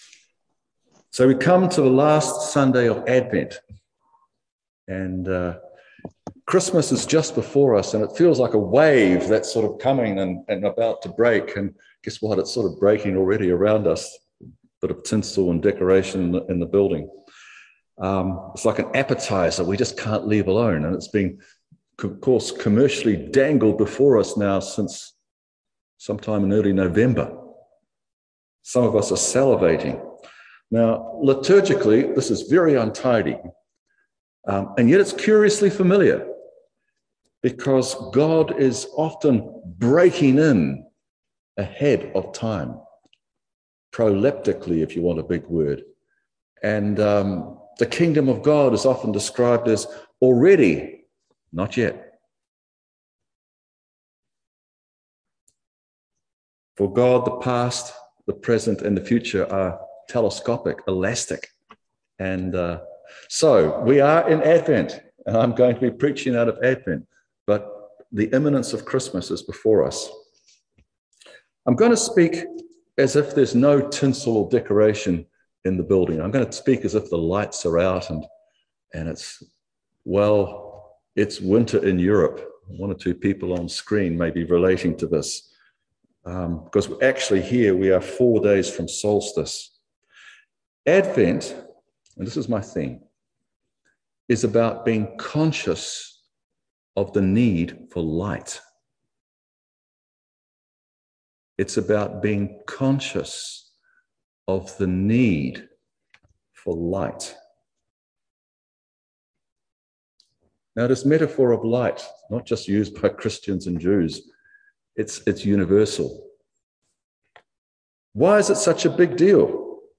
Service Type: Holy Communion